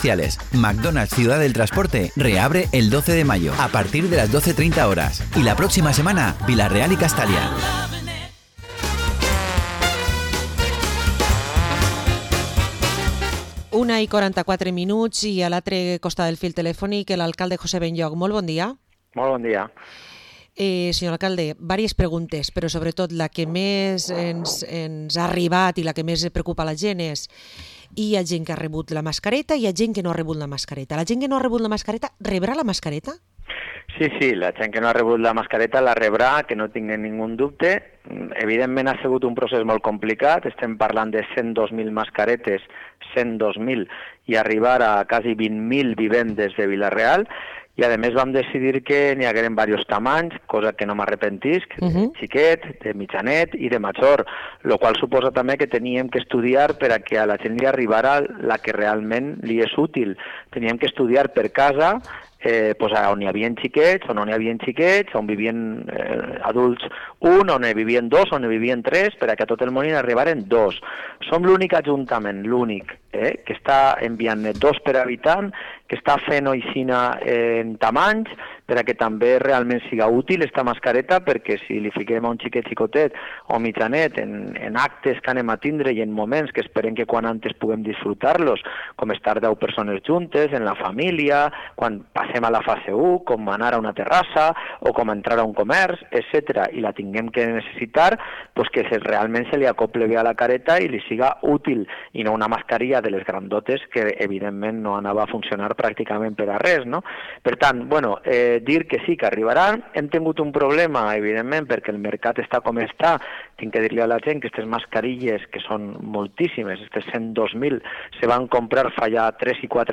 Entrevista a José Benlloch, alcalde de Vila-real